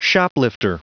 Prononciation du mot shoplifter en anglais (fichier audio)
Prononciation du mot : shoplifter